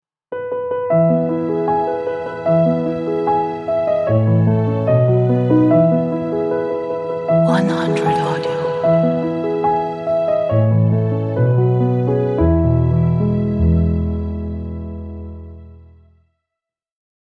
Classical, minimal and exclusive piano logo for any purpose.